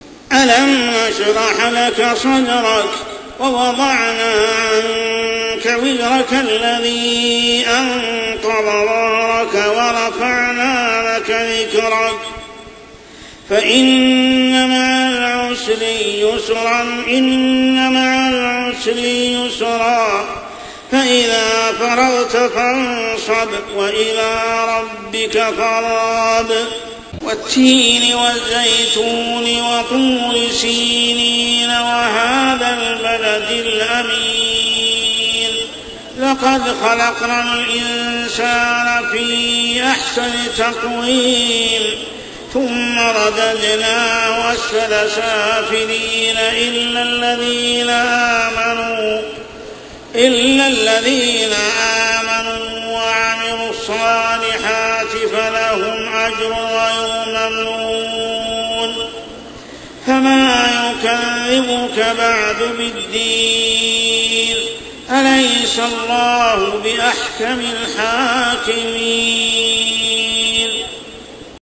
عشائيات شهر رمضان 1426هـ سورة الشرح و التين كاملة | Isha prayer Surah Ash-Sharh and At-Tin > 1426 🕋 > الفروض - تلاوات الحرمين